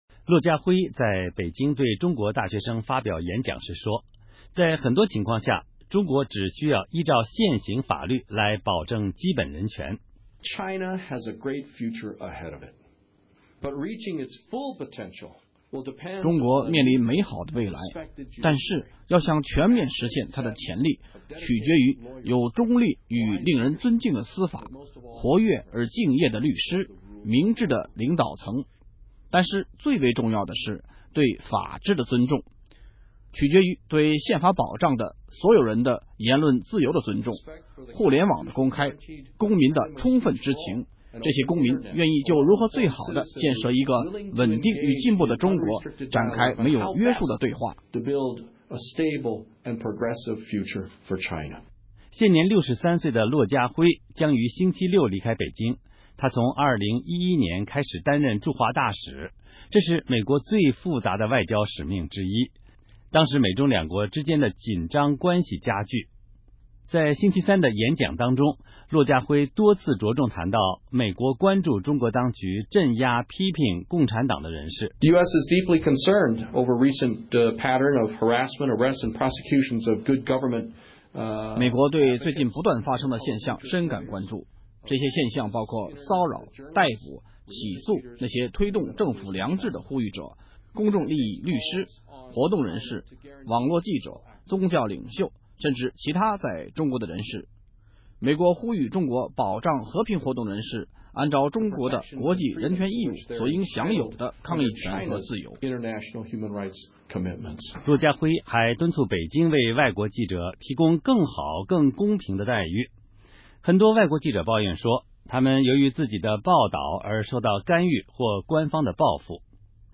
骆家辉（Gary Locke）在北京对中国大学生发表演讲时说，在很多情况下，中国只需要依照现行法律来保障基本人权。